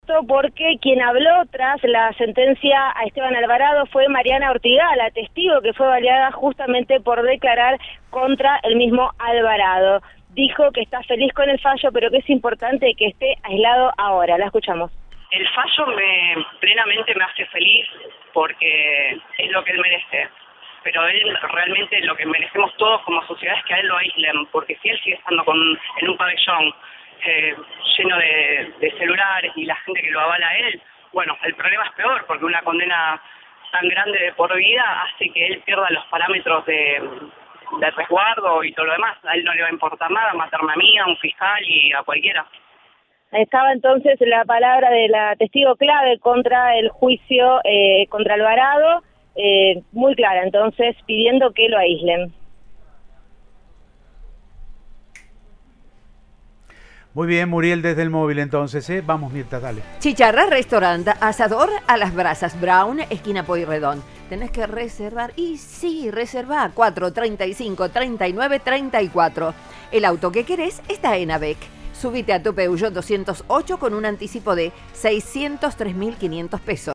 habló con el móvil de Cadena 3 Rosario tras la resolución que le dictó prisión perpetua.